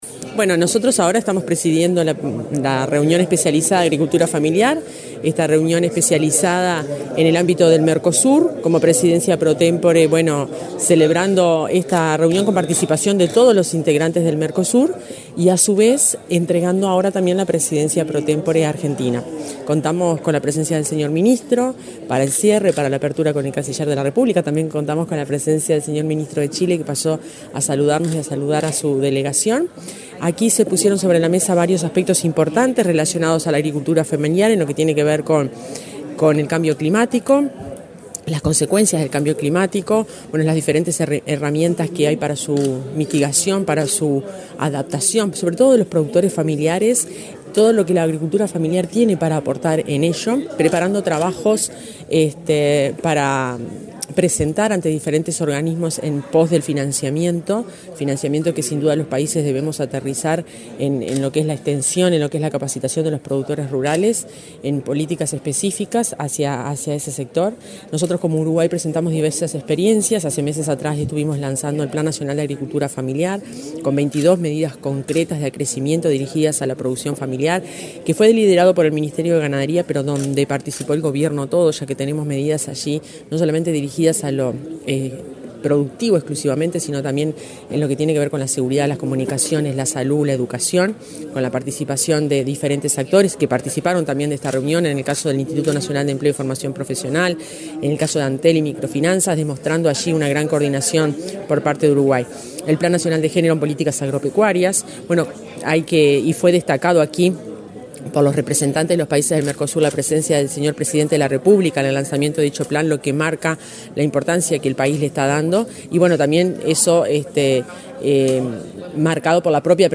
Entrevista a la directora general del Ministerio de Ganadería, Fernanda Maldonado